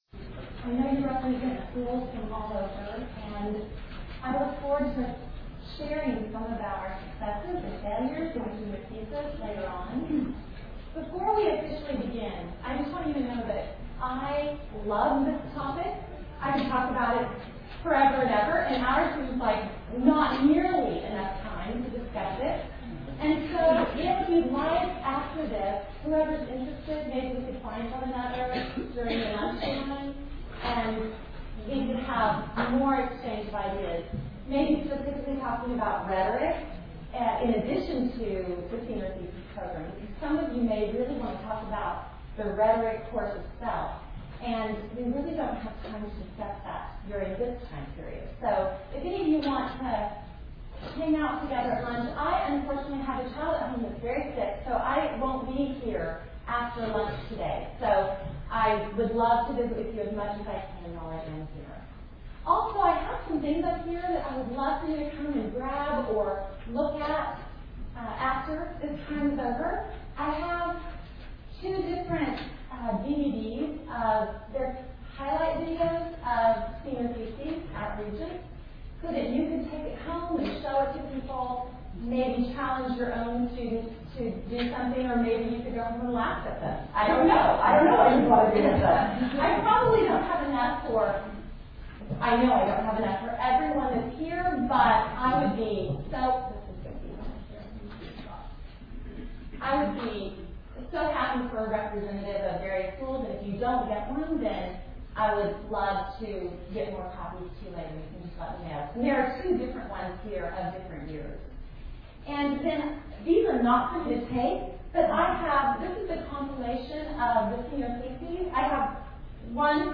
2008 Workshop Talk | 1:01:51 | 7-12, Rhetoric & Composition
Jan 31, 2019 | 7-12, Conference Talks, Library, Media_Audio, Rhetoric & Composition, Workshop Talk | 0 comments